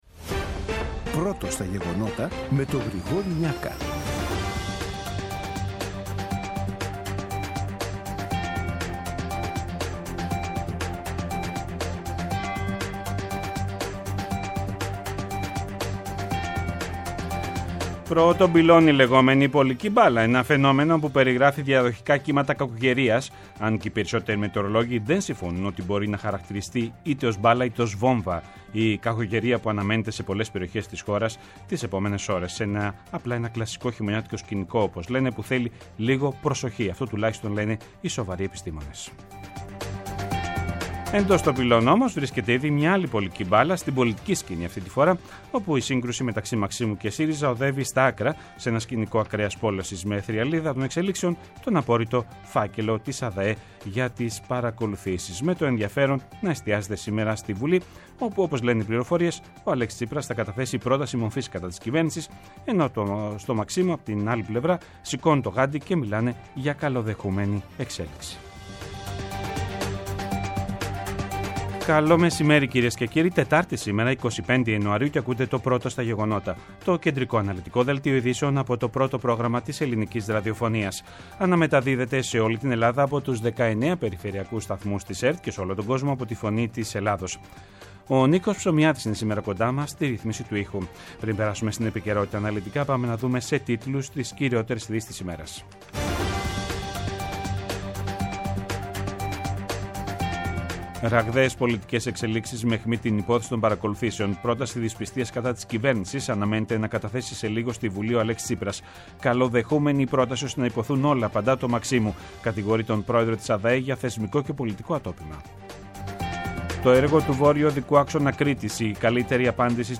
Το κεντρικό ενημερωτικό μαγκαζίνο του Α΄ Προγράμματος, από Δευτέρα έως Παρασκευή στις 14.00. Με το μεγαλύτερο δίκτυο ανταποκριτών σε όλη τη χώρα, αναλυτικά ρεπορτάζ και συνεντεύξεις επικαιρότητας.